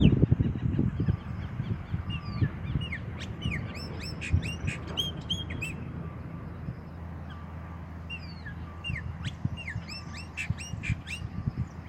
Chopi Blackbird (Gnorimopsar chopi)
Detailed location: Lago Salto Grande
Condition: Wild
Certainty: Observed, Recorded vocal